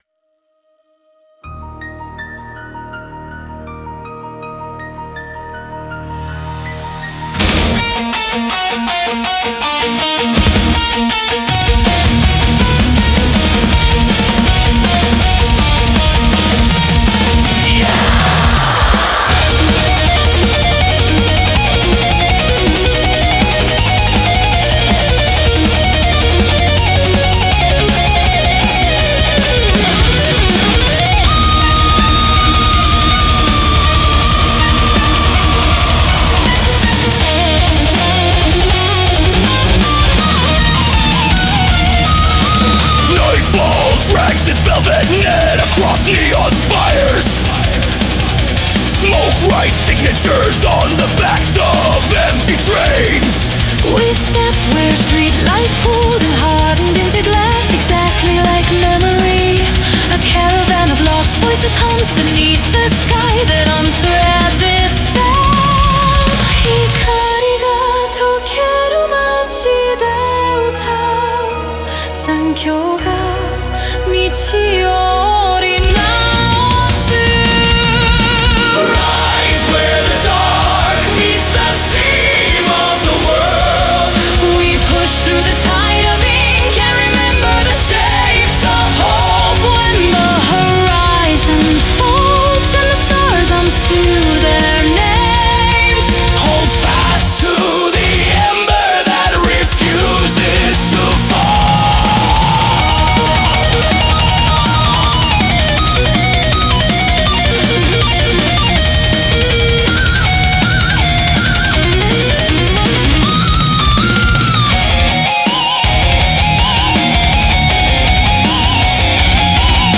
Melodic Death Metal
extreme death metal production guide